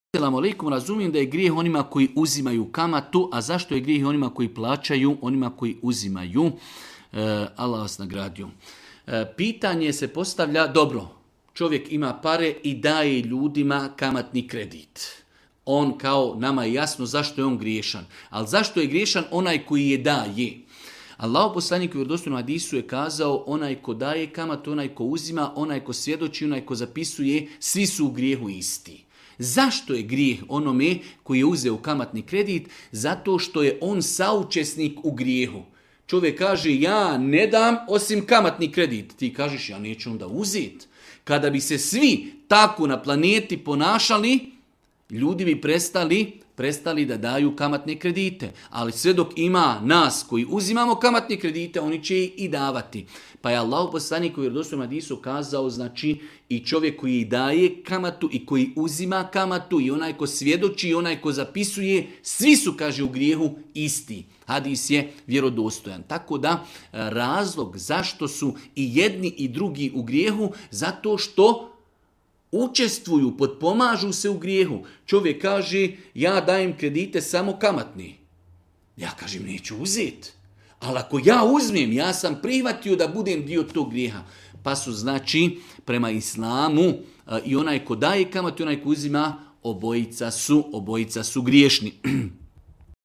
u video predavanju.